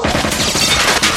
• AUTOMATIC FIRE.wav
AUTOMATIC_FIRE_bSr.wav